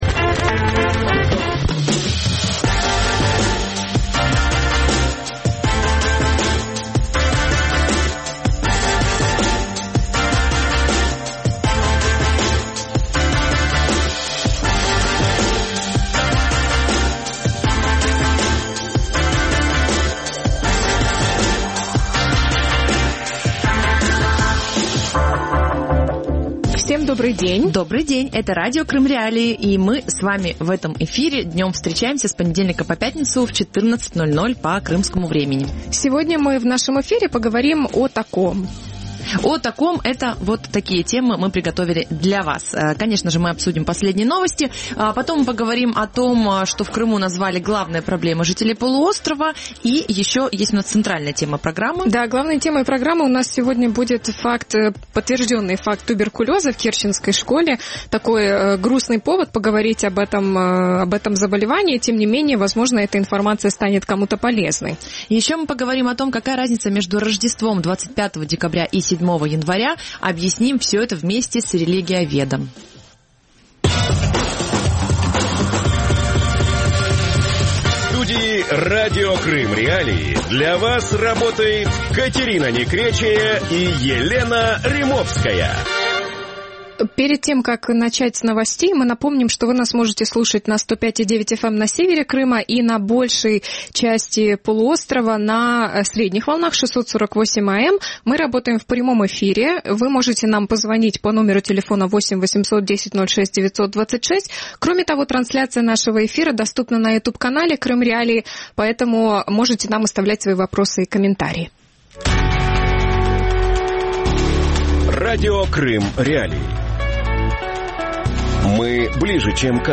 Туберкулез в керченской школе | Дневное ток-шоу